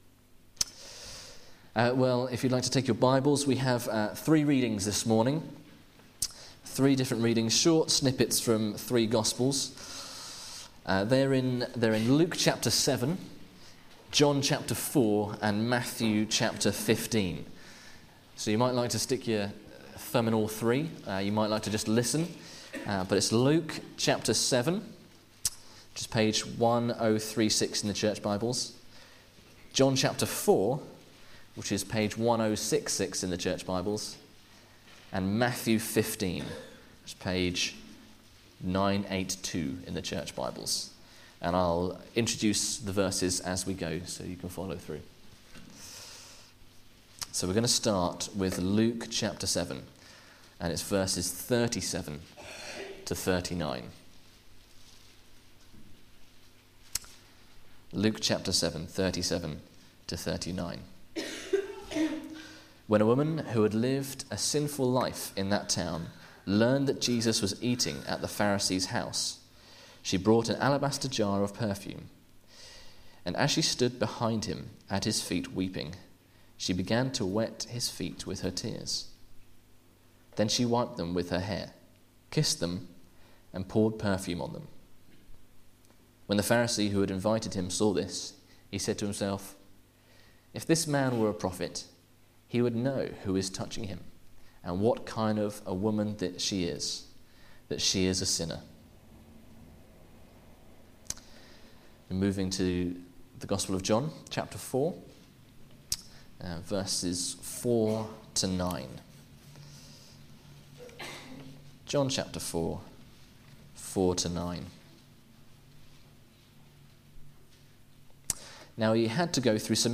Jesus' Interaction with women Sermon